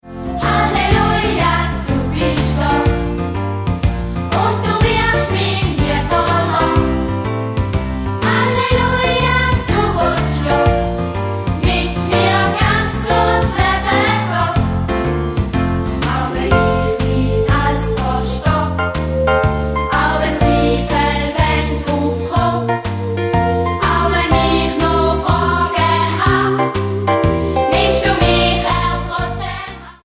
die vielen kurzen, eingängigen Refrains